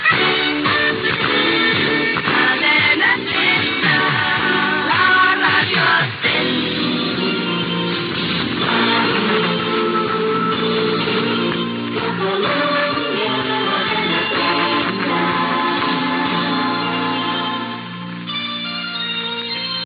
Indicatiu de la cadena i de l'emissora